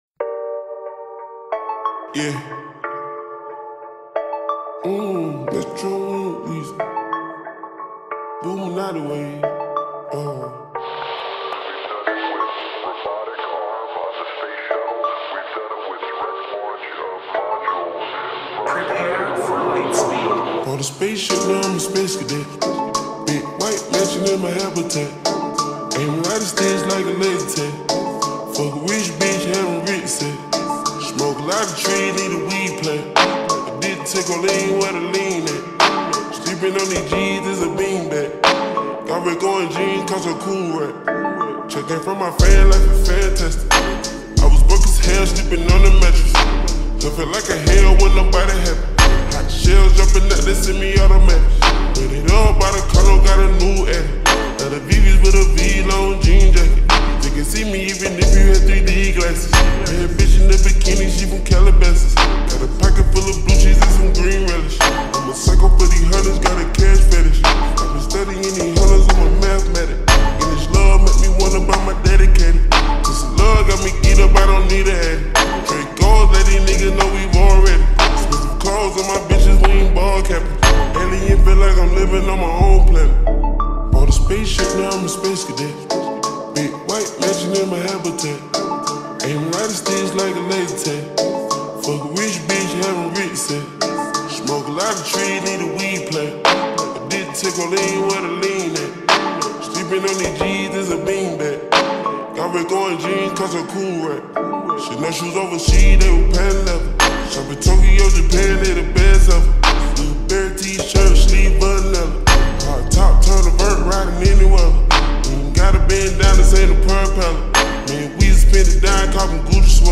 با ریتمی کند شده